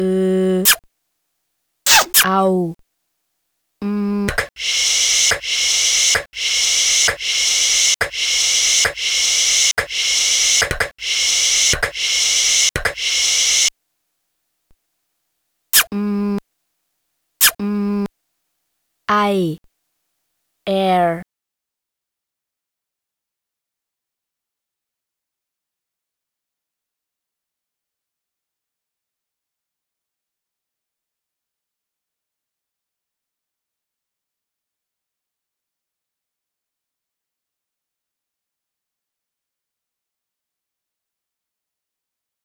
phonetic typewriter riolarett
konkrete poesie aus dem kinderzimmer - liebenswert und durch die repetitive passage zwischen sek 5 und sek 15 nicht ohne die nötige verschattung. an dieser stelle koennte man sich dennoch einen kick kuerzer fassen - die intention ist spätestens ab sek 10 klar. der schluss öffnet sich unerwartet - eine schöne symetrische wendung. nach der letzten silbe erwartet man noch etwas - was aber nicht kommt. soll das so sein?